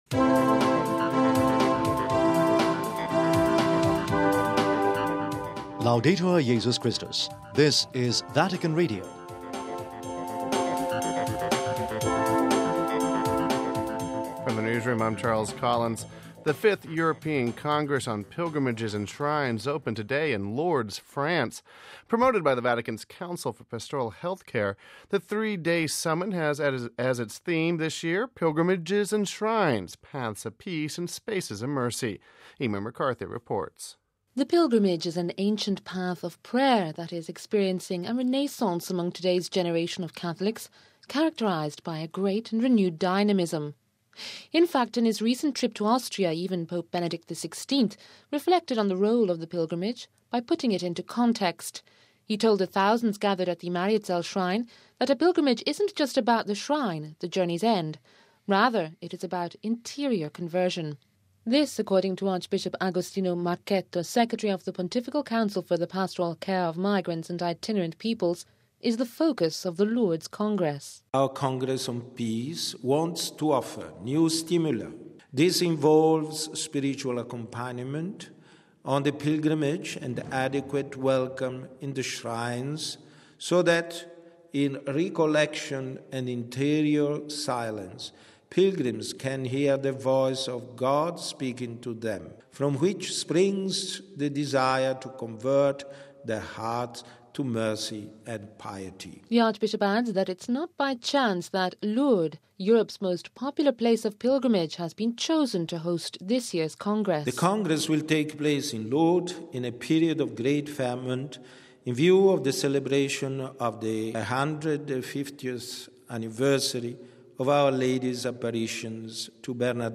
We have this report…